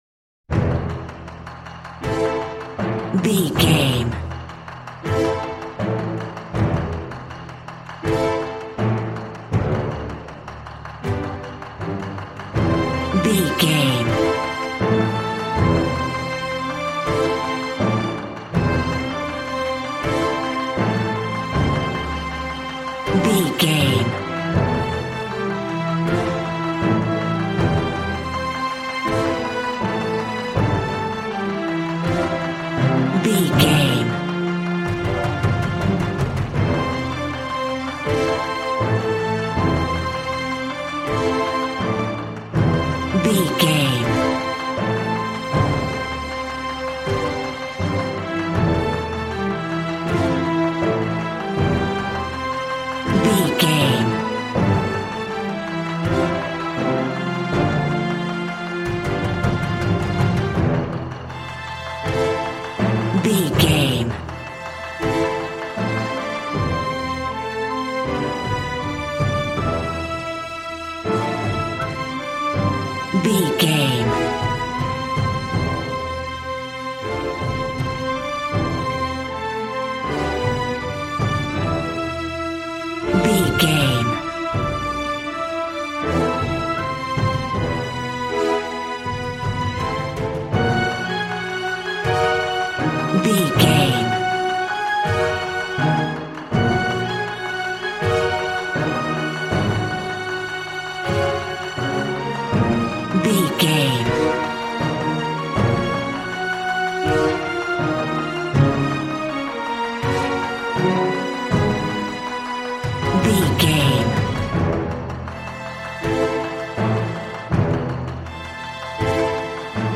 Aeolian/Minor
brass
strings
violin
regal